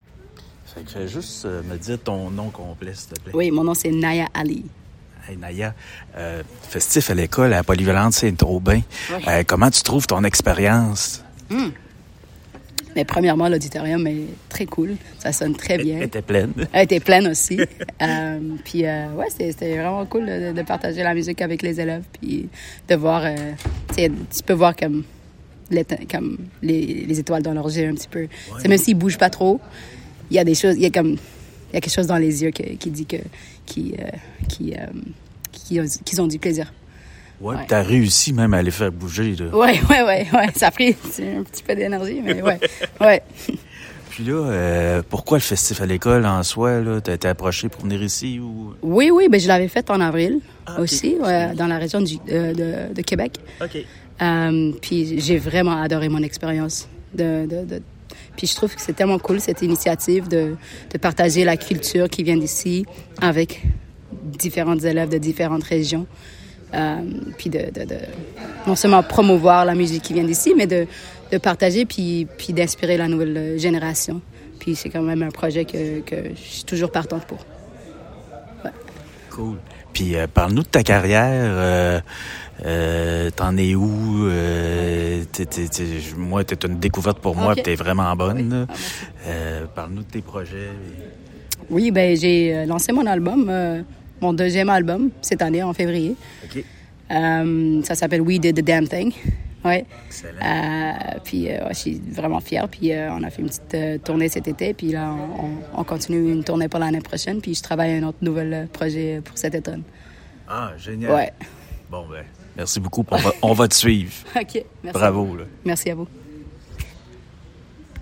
L’ambiance était bonne cet après-midi à la polyvalente Saint-Aubin de Baie-Saint-Paul, où la rappeuse d’origine éthiopienne Naya Ali a électrisé l’auditorium dans le cadre du Festif! à l’école. Connue pour sa voix rauque, son flow percutant et sa confiance inébranlable, l’artiste a livré une performance qui a su captiver l’attention des étudiants.